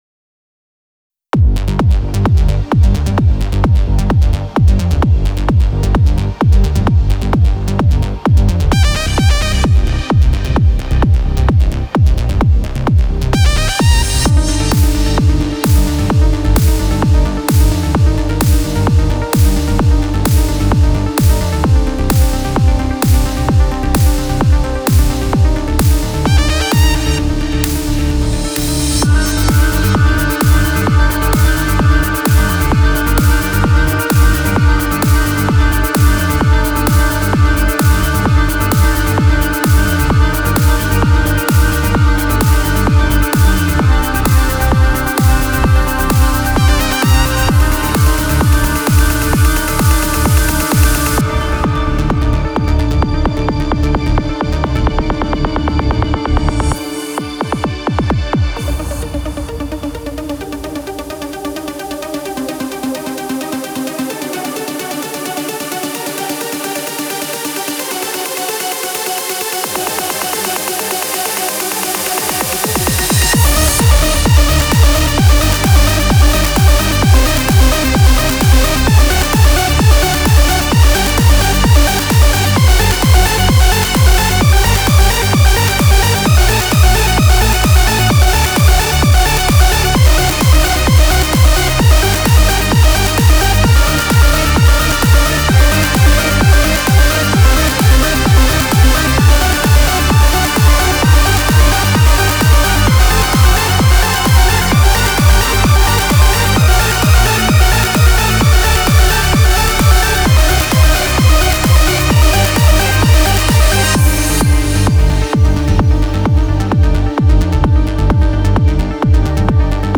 BEST ELECTRO A-F (35)